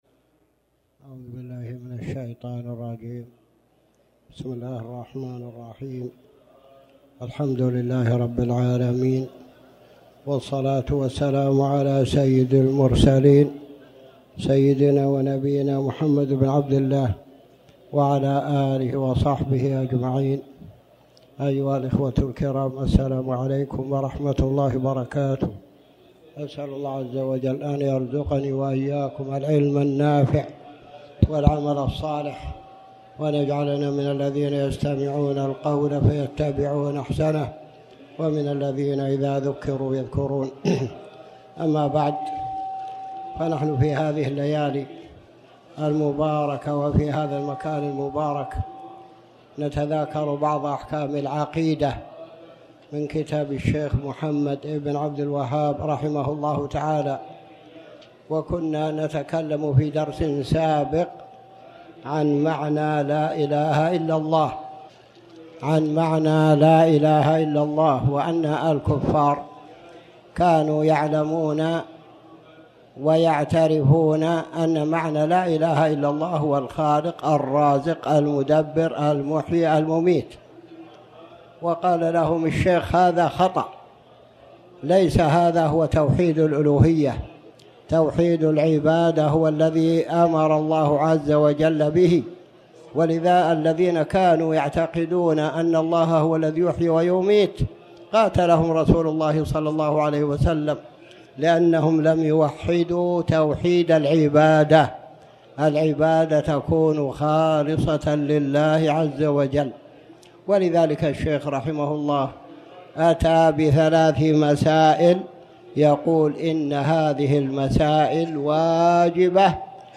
تاريخ النشر ٢٢ جمادى الآخرة ١٤٣٩ هـ المكان: المسجد الحرام الشيخ